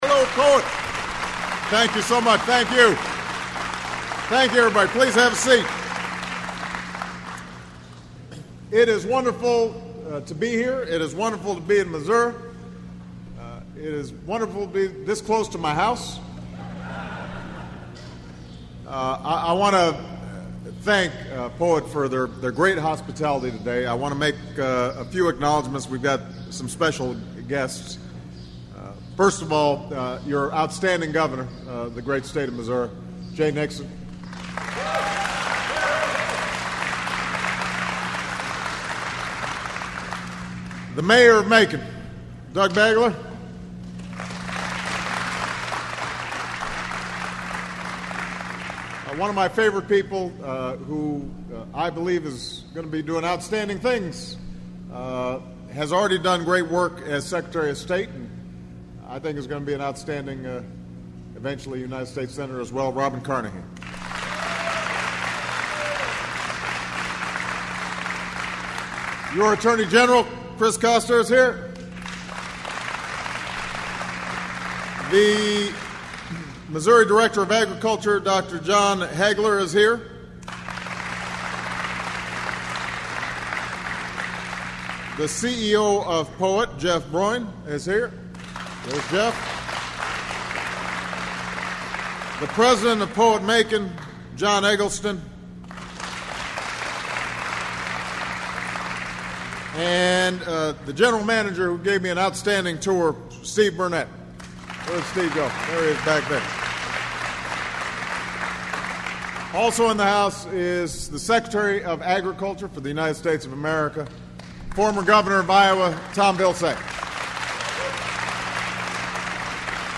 President Obama speaks in Macon, MO
The President spoke for about 10 minutes at the plant, telling the 45 workers there that his administration is dedicated to making clean fuels a staple of the American economy in the immediate future.